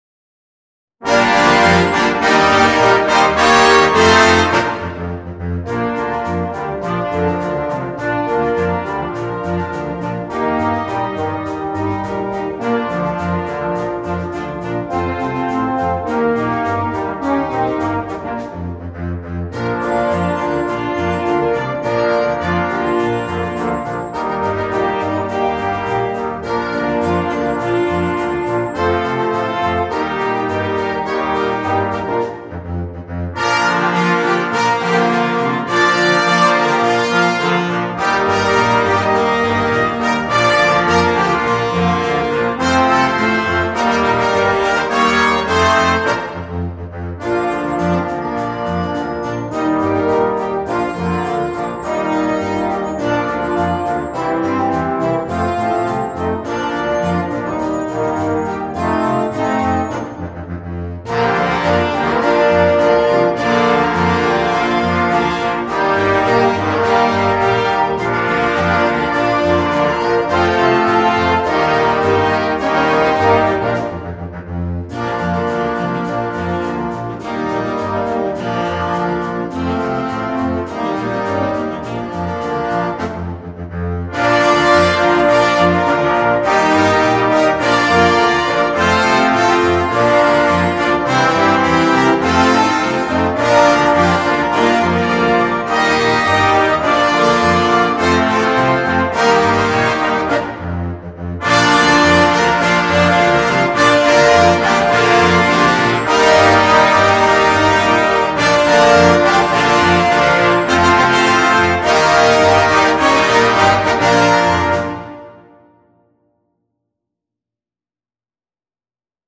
Noten für Blasorchester
komplette Besetzung
ohne Soloinstrument
Unterhaltung